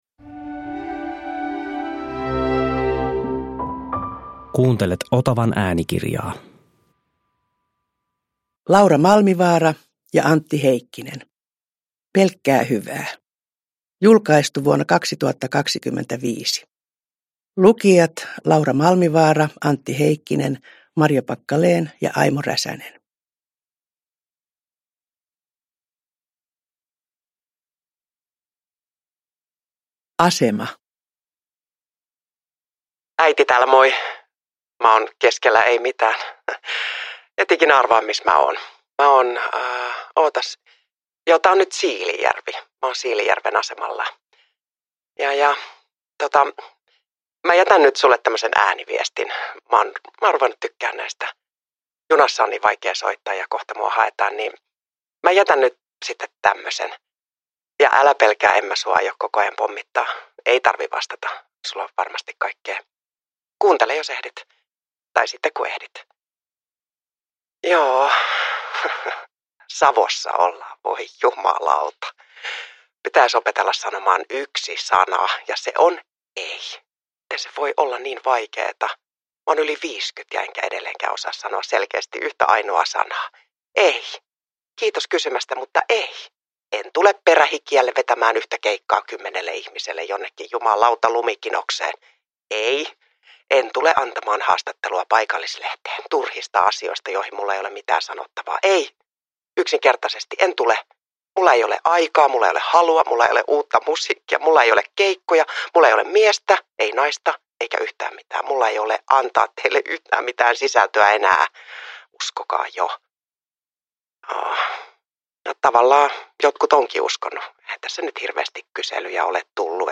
Pelkkää hyvää – Ljudbok